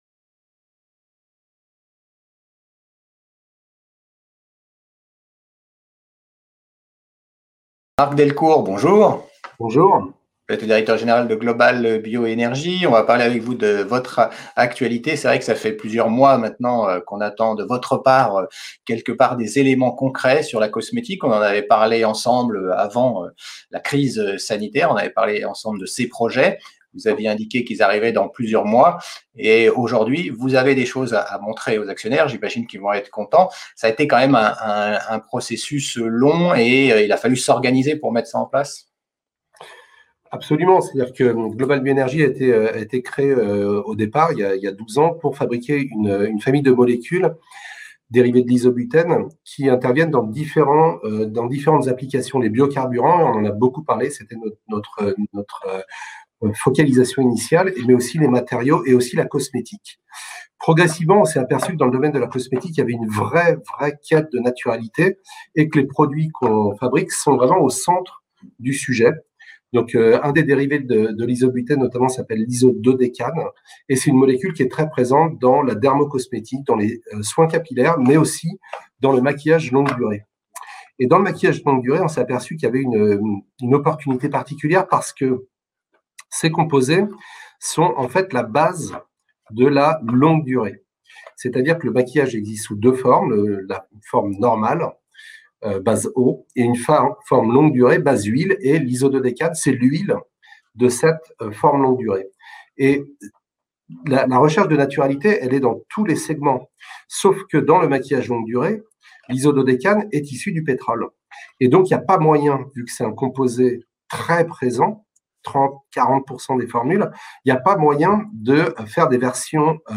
Category: L'INTERVIEW